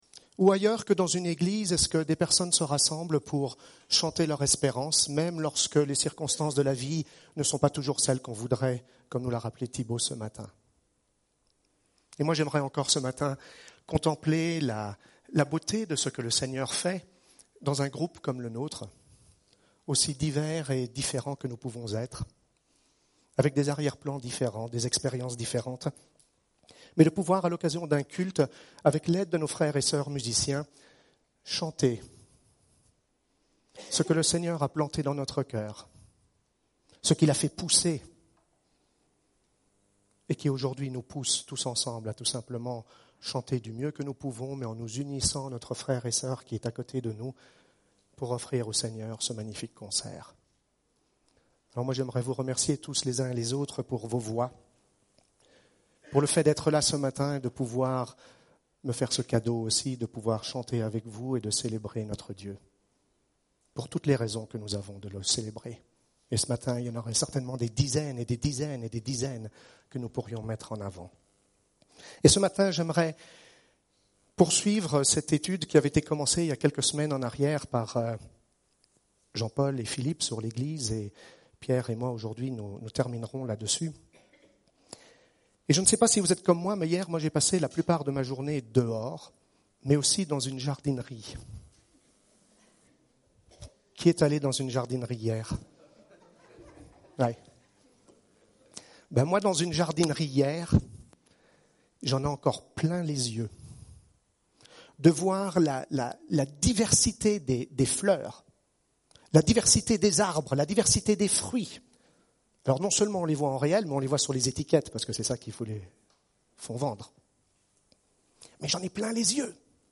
Preacher: Conseil d'anciens | Series:
Culte du 14 avril